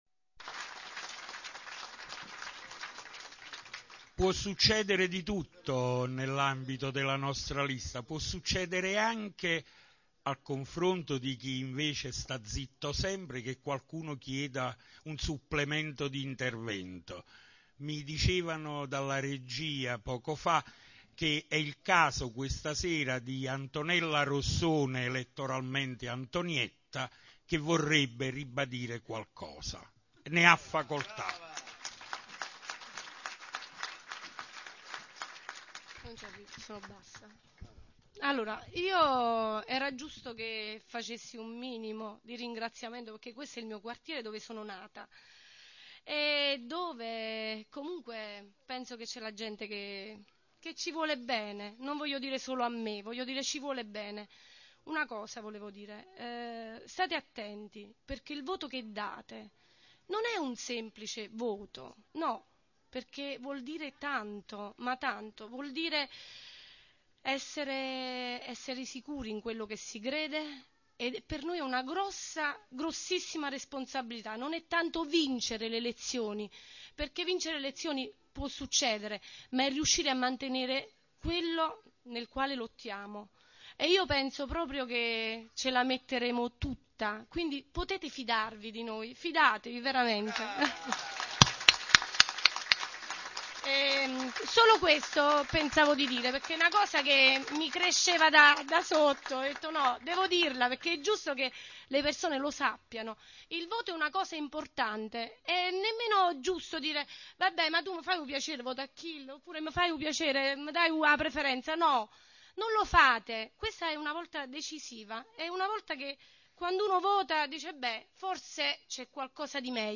31-06-04 - Audio del comizio in via Manzoni (Mp3 0,5 MB)